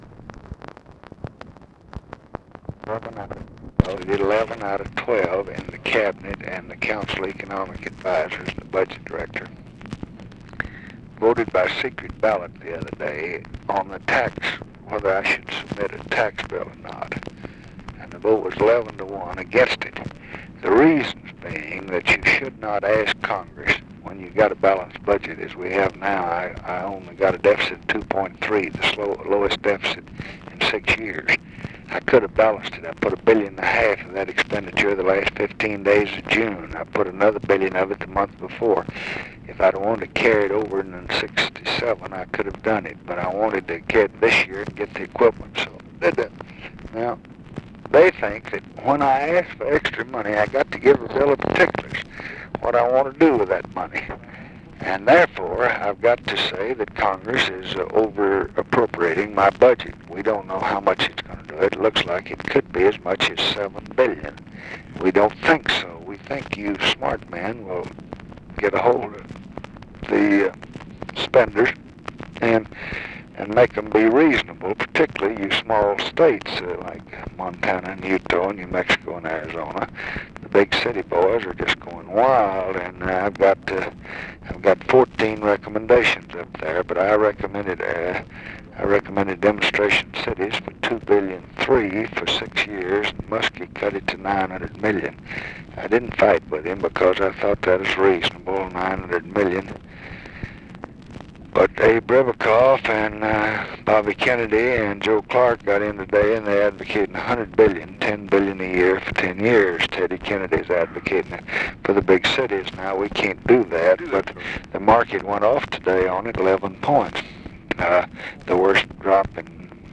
Conversation with CLINTON ANDERSON, August 16, 1966
Secret White House Tapes